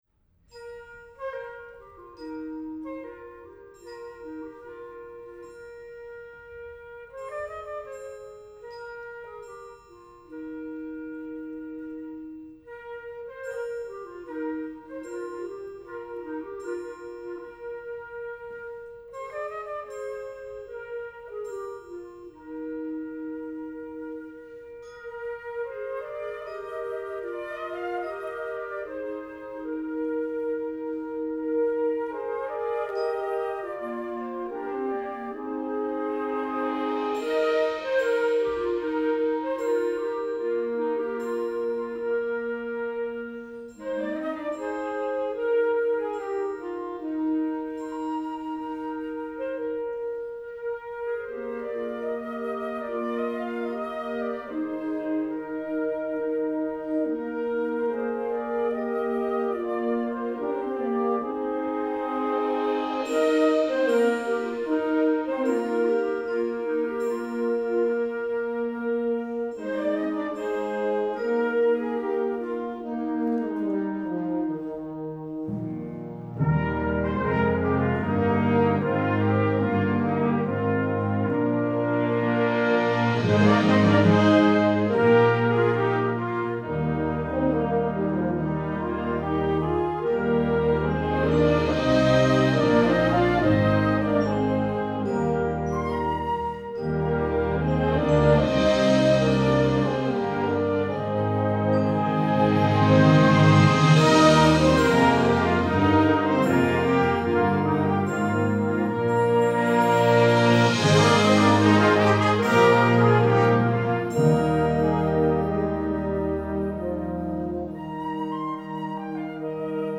Partitions pour orchestre d'harmonie.